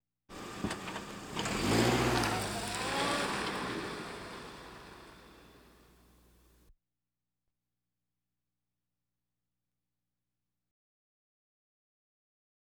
Sports Car Drive Away Sound
transport